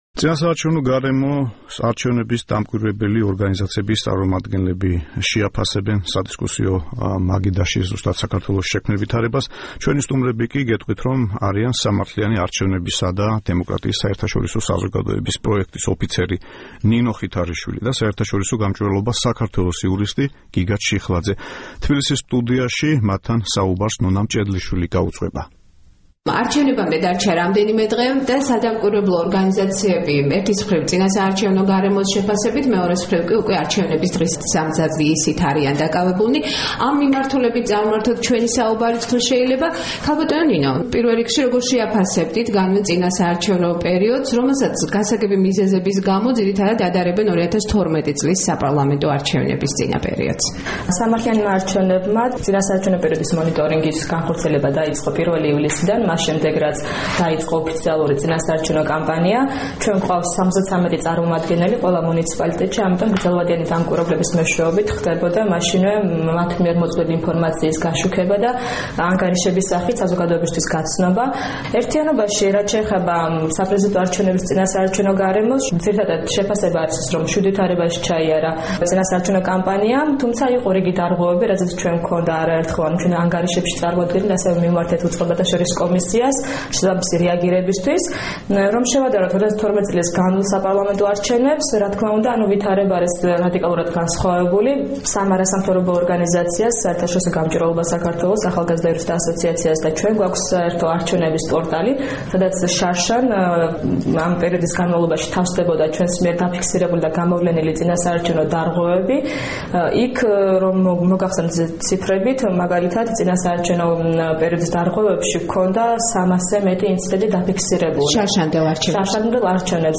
დღეს, სადისკუსიო მაგიდასთან, წინასაარჩევნო გარემოს შეაფასებენ არჩევნების დამკვირვებელი ორგანიზაციების წარმომადგენლები